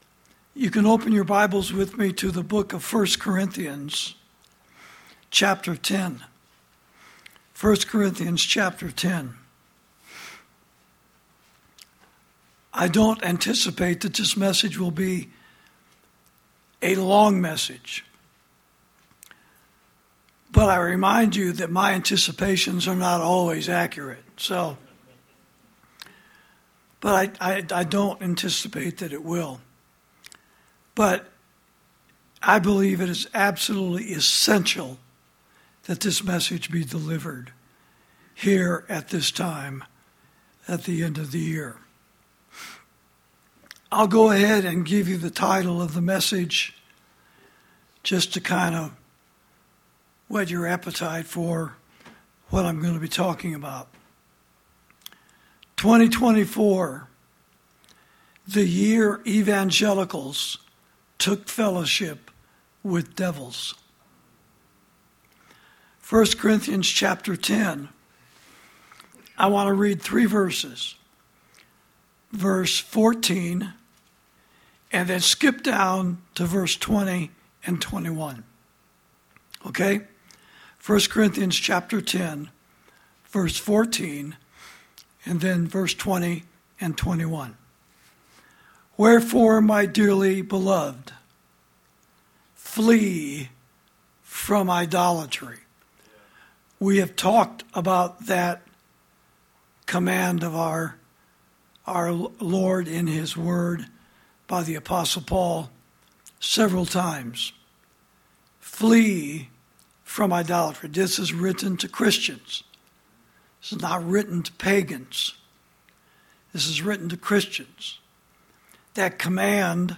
Sermons > 2024: The Year Evangelicals Took Fellowship With Devils